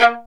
Index of /90_sSampleCDs/Roland L-CD702/VOL-1/STR_Violin 1-3vb/STR_Vln1 _ marc
STR VLN JE13.wav